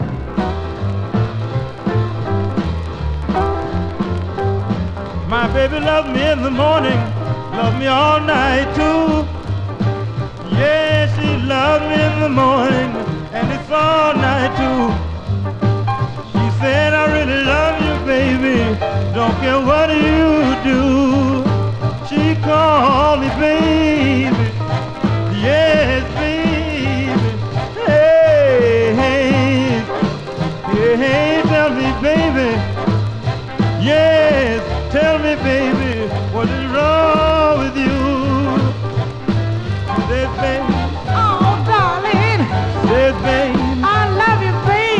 20代とは思えない貫禄ある声で、聴けば聴くほど惚れ込む素晴らしさです。
Rhythm & Blues, Ballad　UK　12inchレコード　33rpm　Mono